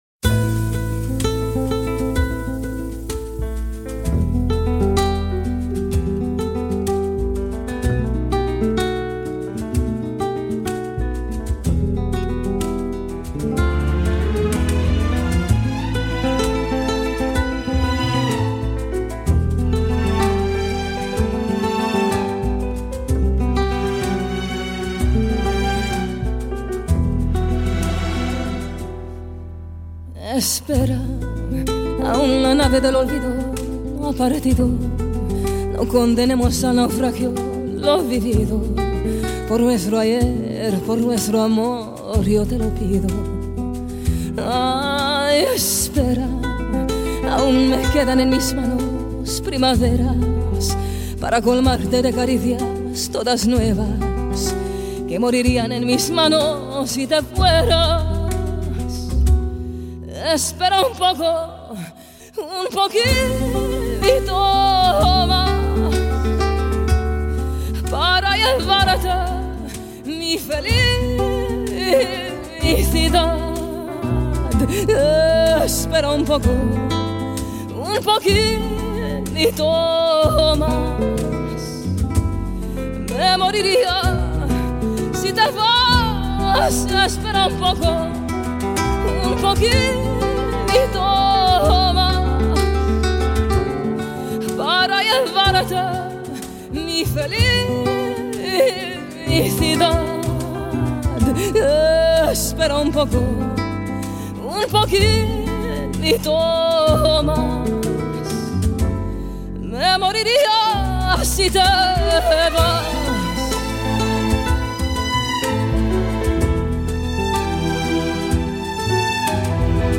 ترانه اسپانیایی cancion española